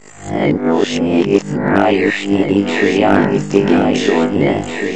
Descarga de Sonidos mp3 Gratis: robot 5.